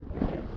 PixelPerfectionCE/assets/minecraft/sounds/mob/polarbear/step4.ogg at mc116